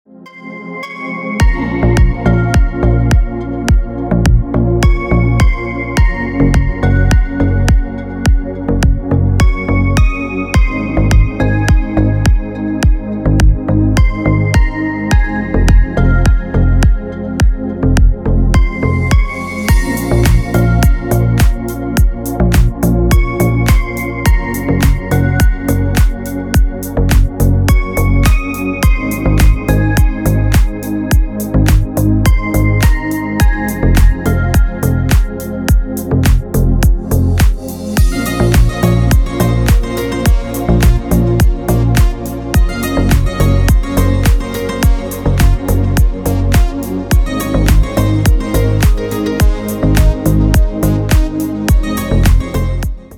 Красивые мелодии и рингтоны